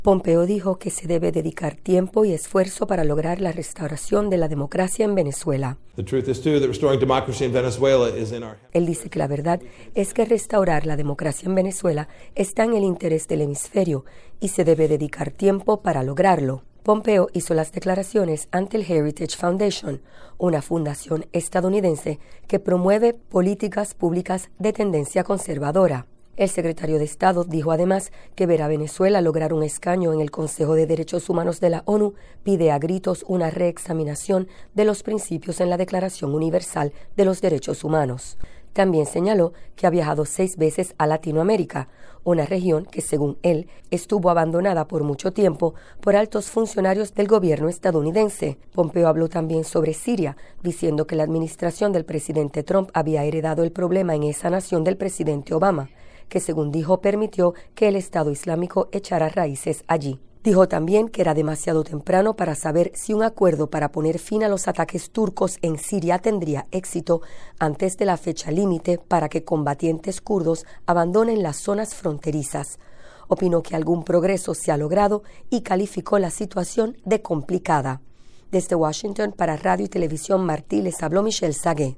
El jefe de la diplomacia estadounidense hizo las declaraciones ante el Heritage Foundation, una entidad que promueve políticas públicas de tendencia conservadora.